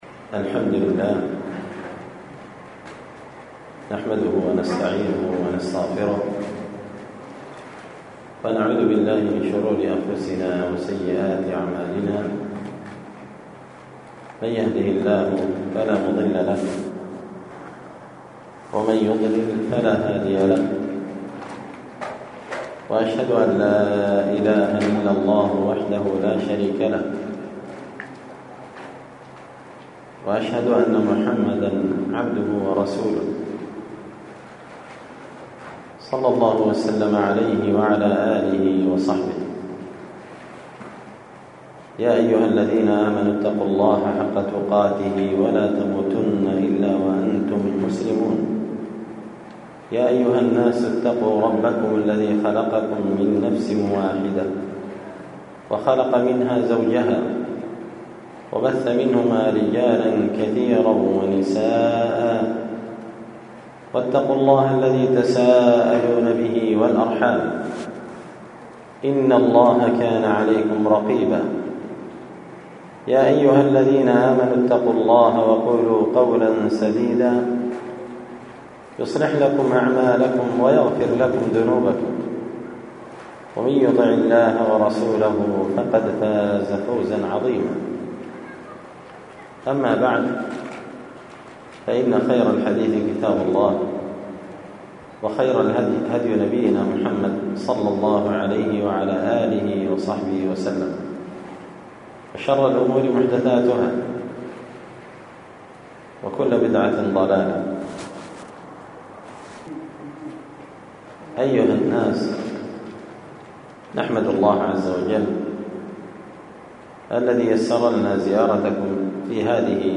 ألقيت هذه المحاضرة بمسجد أهل السنة ضبوت-المهرة-اليمن تحميل…